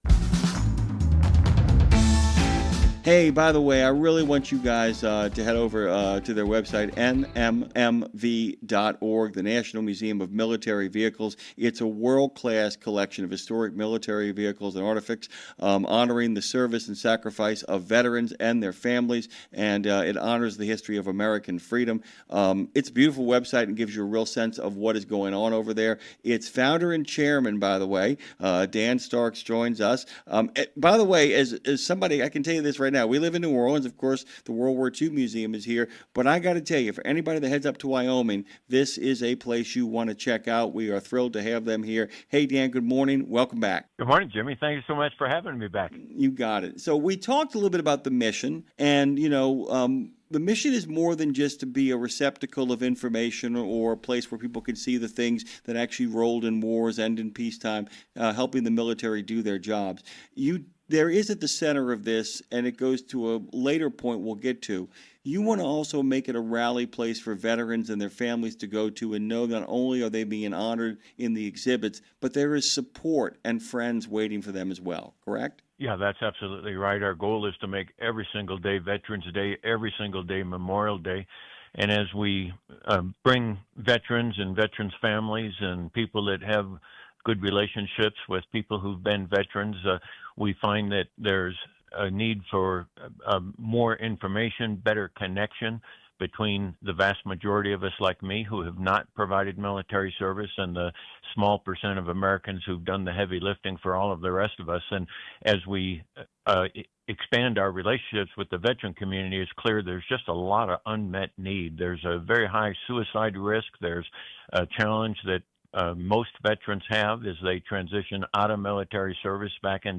Road Gang Radio Interview | National Museum of Military Vehicles | Largest Collection of American Military Vehicles